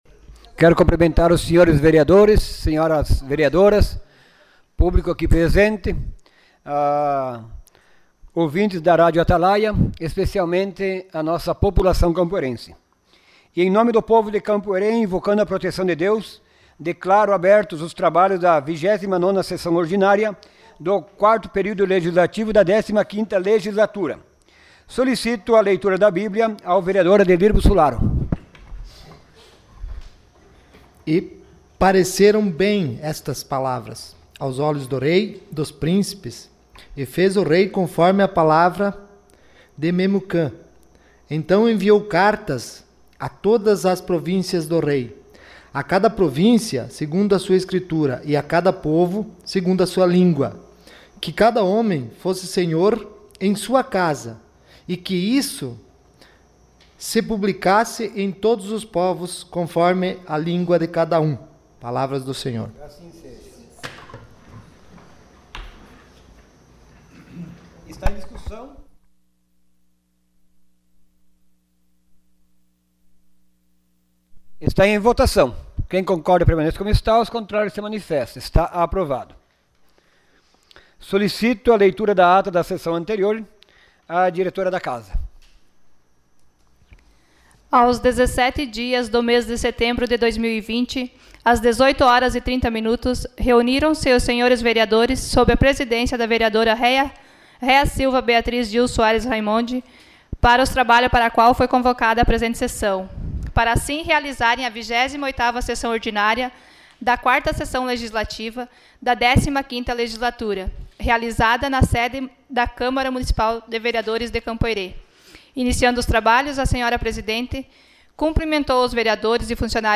SESSÃO ORDINÁRIA DIA 24 DE SETEMBRO DE 2020